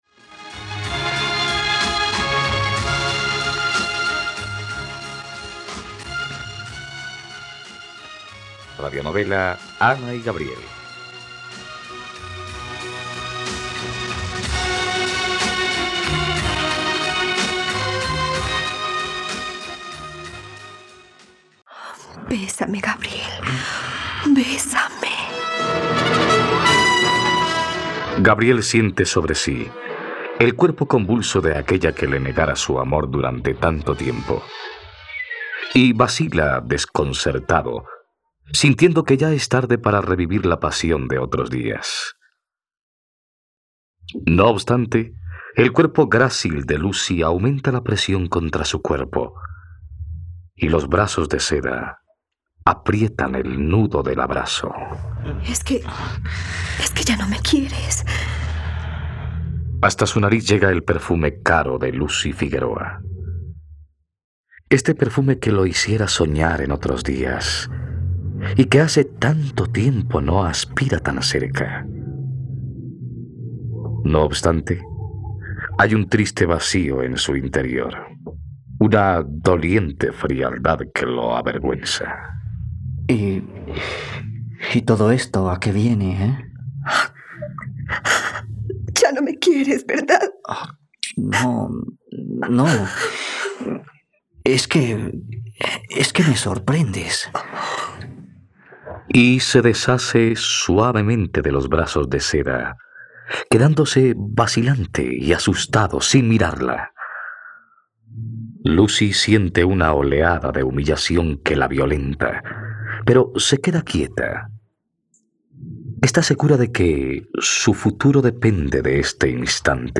Ana y Gabriel - Radionovela, capítulo 42 | RTVCPlay